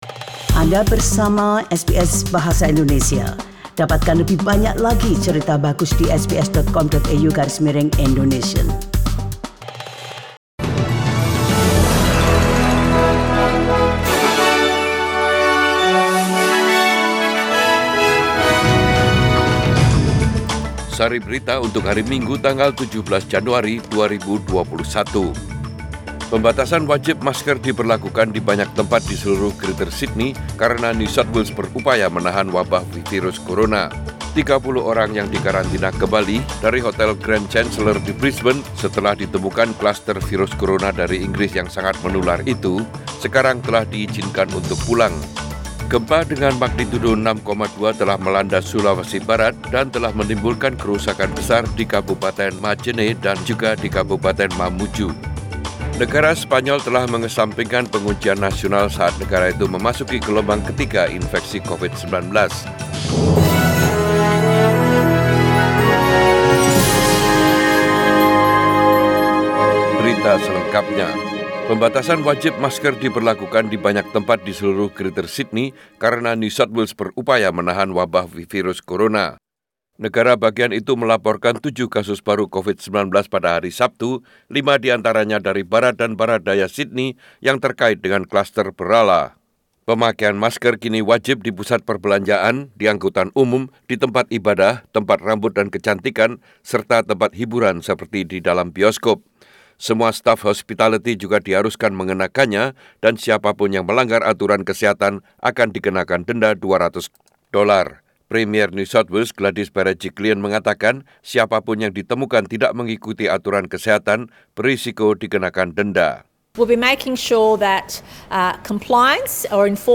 SBS Radio News in Bahasa Indonesia - 17 January 2021